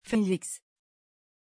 Pronuncia di Feliks
pronunciation-feliks-tr.mp3